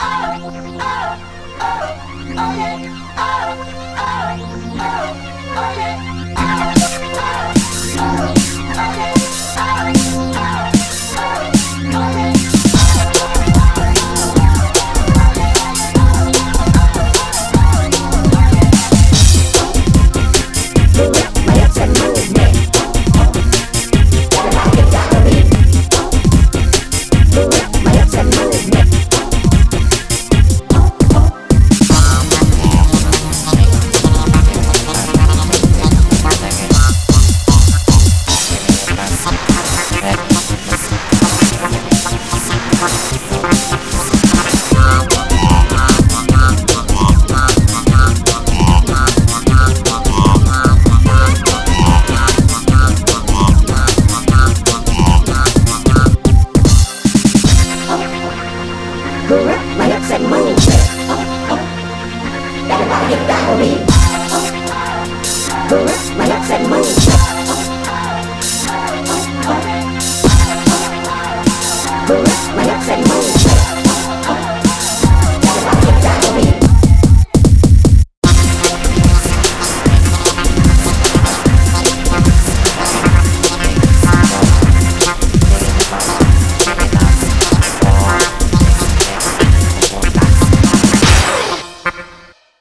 Audio QualityPerfect (High Quality)